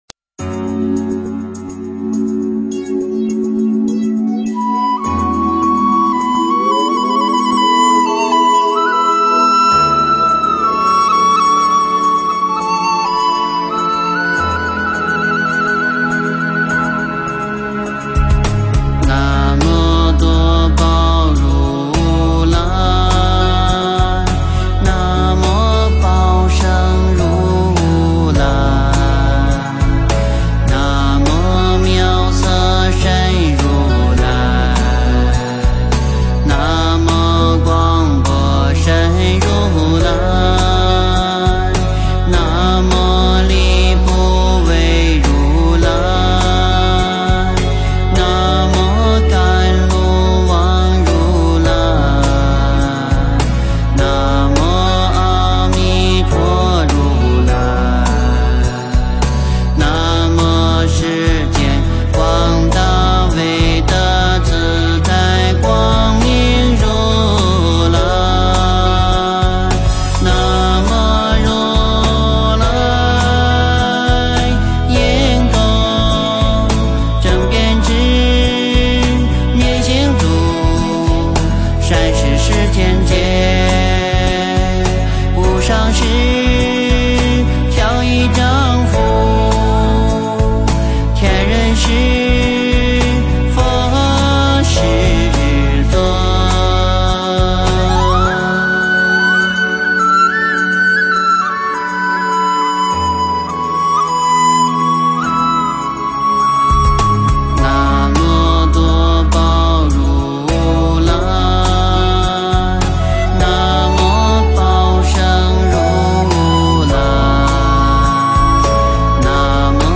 诵经
佛音 诵经 佛教音乐 返回列表 上一篇： 般若心经 下一篇： 佛母准提神咒 相关文章 普门颂 普门颂--未知...